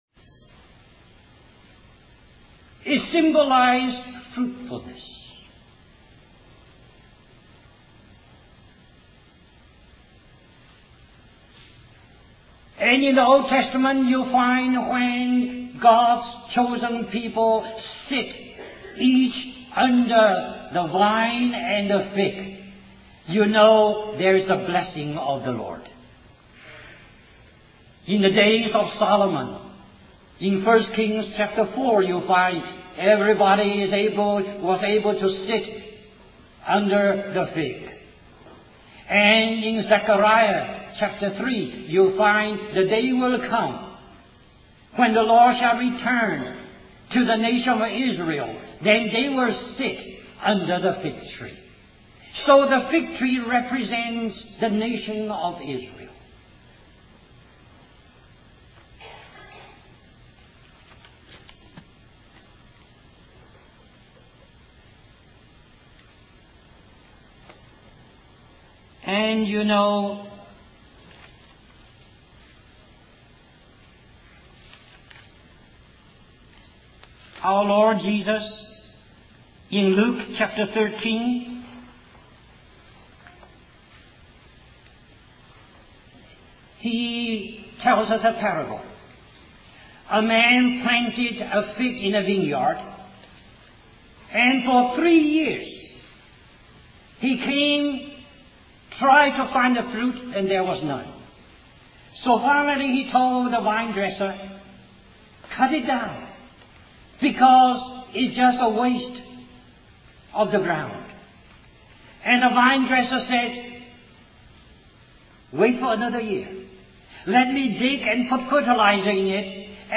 1989 Christian Family Conference Stream or download mp3 Summary This message is a continuation of this message .